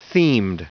Prononciation du mot themed en anglais (fichier audio)
Prononciation du mot : themed